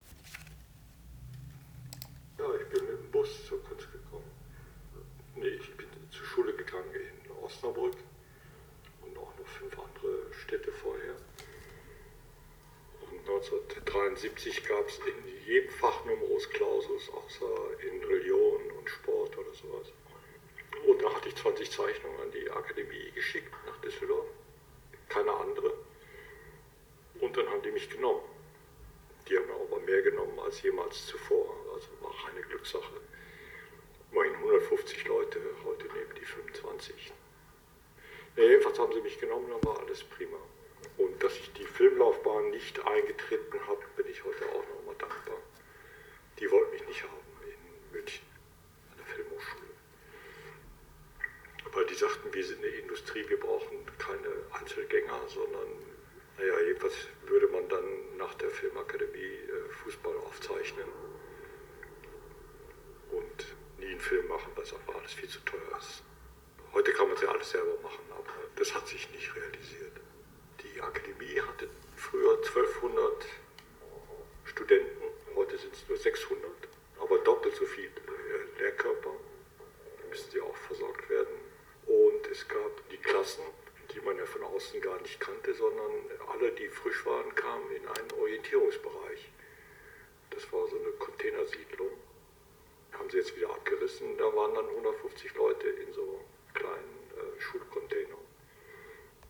Interview Audioarchiv Kunst: Thomas Schütte und seine Aufnahme in die Akademie